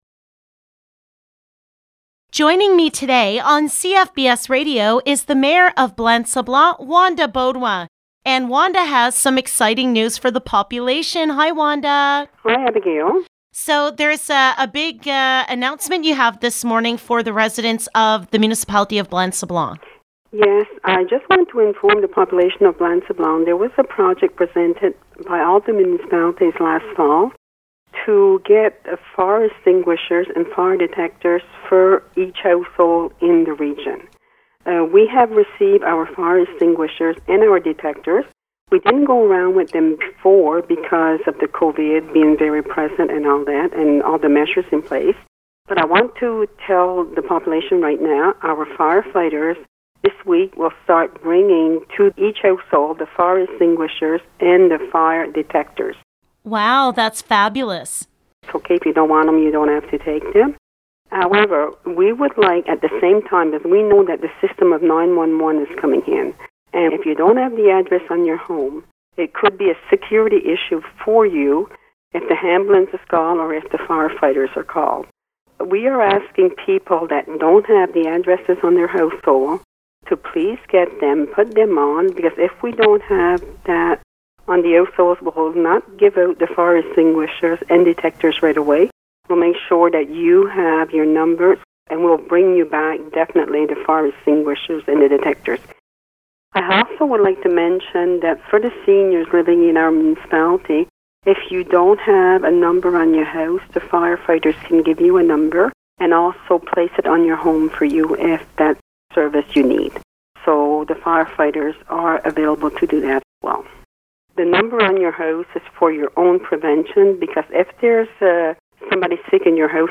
An interview update from the Mayor of the Municipality of Blanc-Sablon, Wanda Beaudoin, regarding: